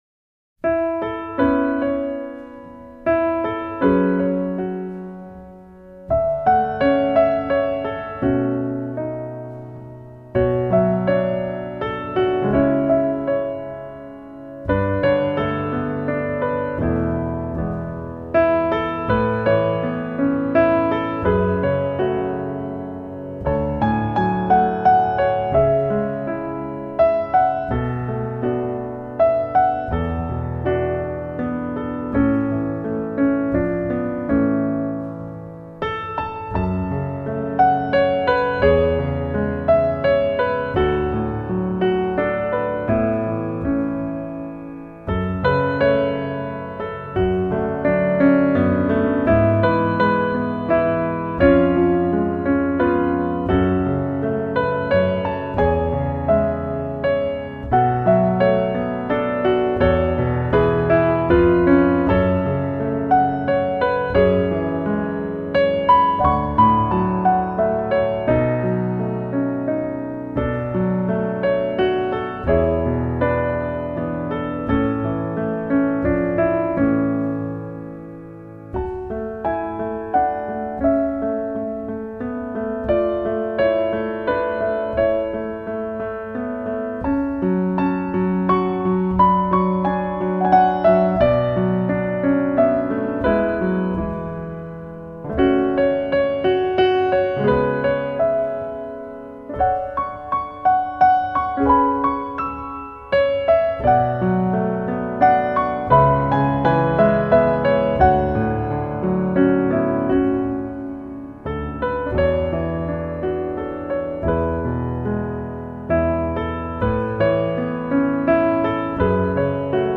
私の初めてのピアノ・ソロのアルバムです。
穏やかで静かな余韻が残ってくだされば、もう、それだけで幸せです。